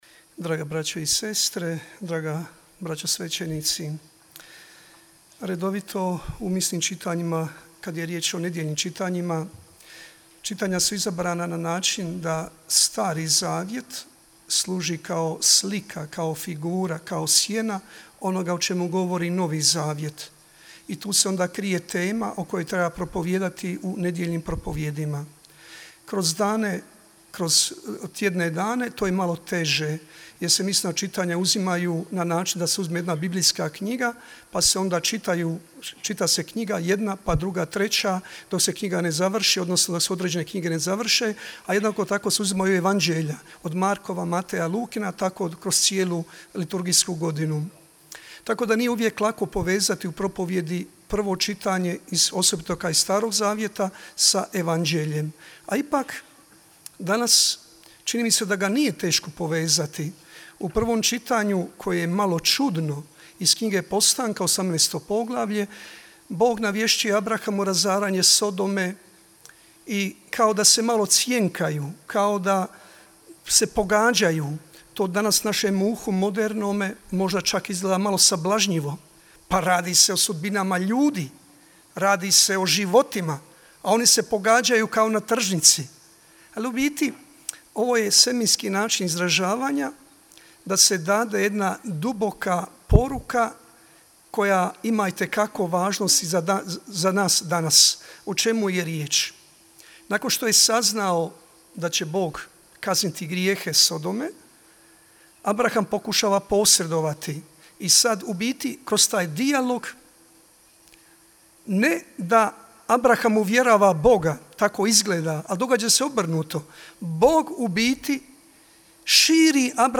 Večernjom svetom misom na vanjskom oltaru crkve sv. Jakova počela je 28. međunarodna duhovna obnova za svećenike u Međugorju